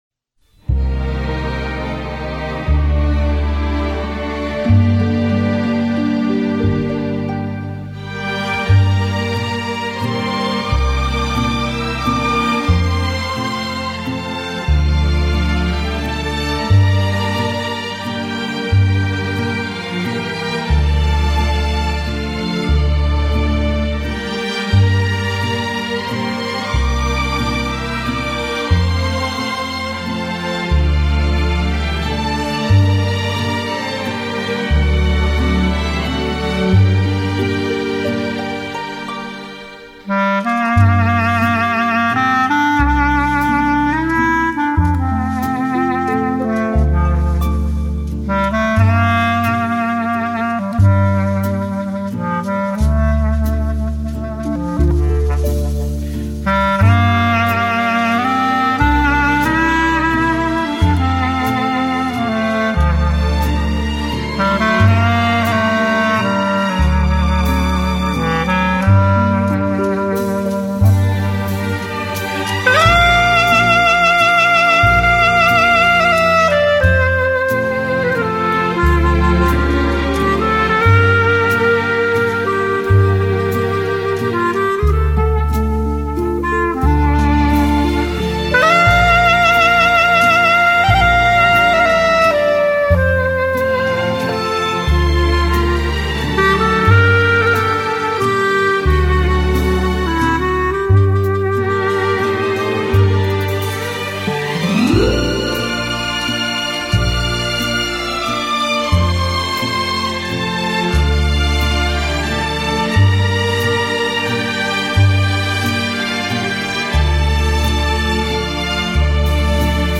Hallgassátok csak, milyen csudajó benne a klarinét!